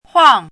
怎么读
huàng huǎng
huang4.mp3